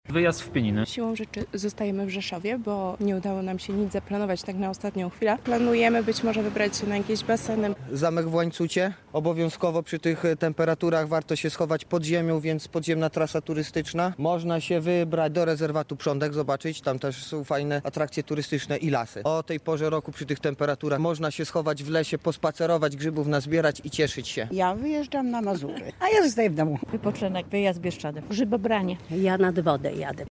Mieszkańcy Rzeszowa planują długi weekend. Gdzie się wybiorą? [SONDA]